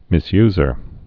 (mĭs-yzər)